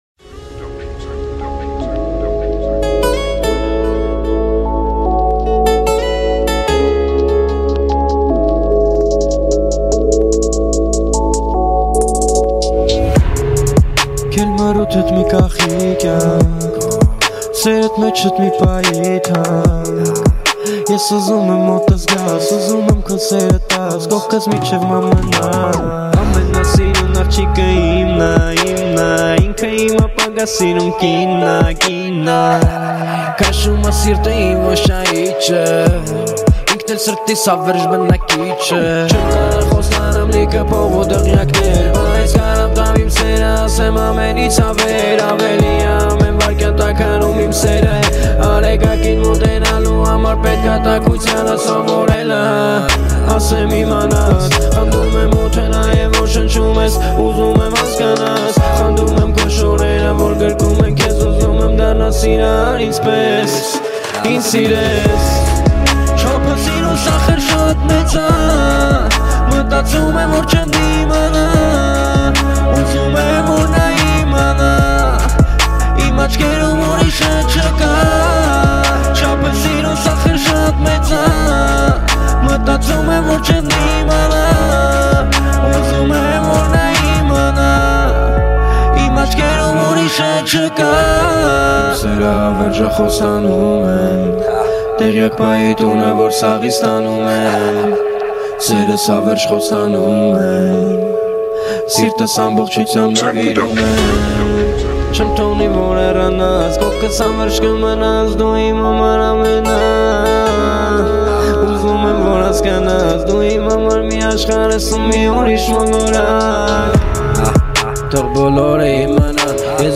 Армянская музыка, Armenian Rap, Erger 2019